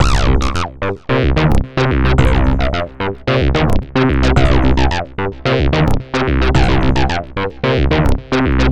UR 303 acid bass 1 b.wav